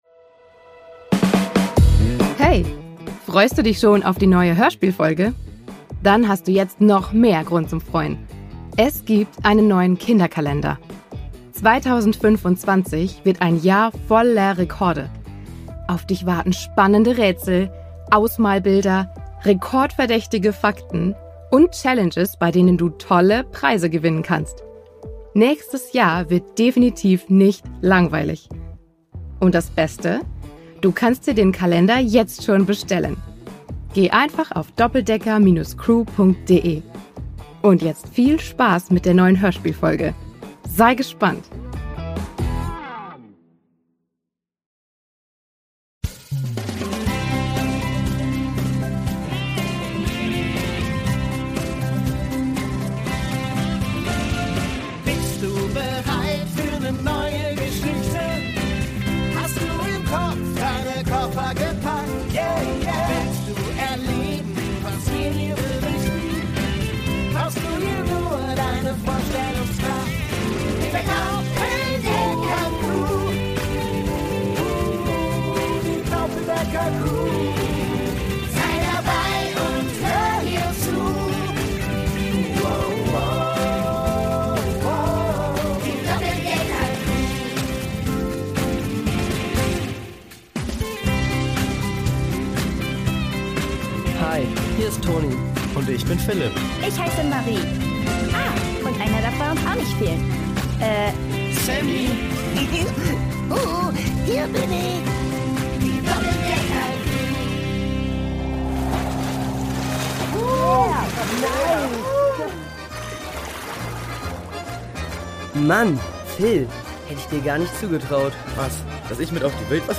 Brasilien 3: Tonis Wüstenwanderung | Die Doppeldecker Crew | Hörspiel für Kinder (Hörbuch) ~ Die Doppeldecker Crew | Hörspiel für Kinder (Hörbuch) Podcast